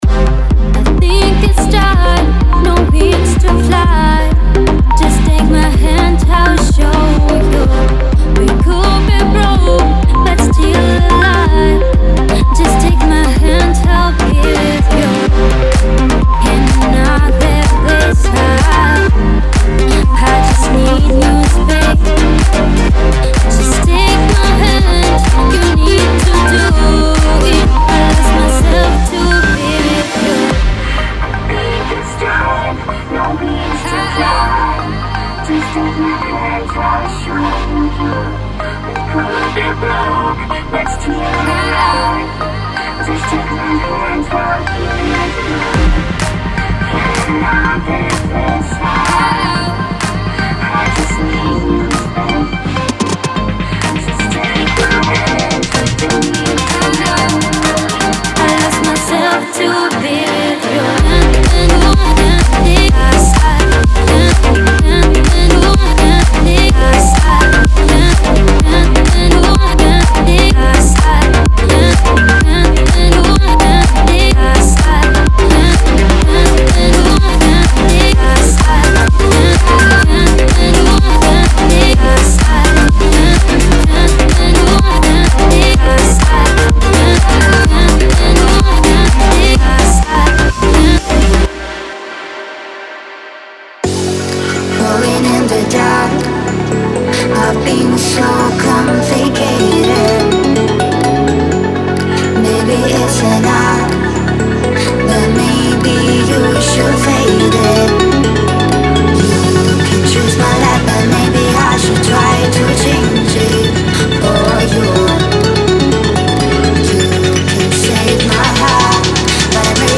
立即为您的音乐作品获取一些很棒的人声和内部样本！
轻松创建您的下一个击中图表的室内音轨，并在其顶部放一个令人难忘的令人赞叹的人声无伴奏合唱音轨！
除了易于使用的构建工具包之外，我们还包括一些语音词，短语和广告素材库，可为您提供完整的语音工具包。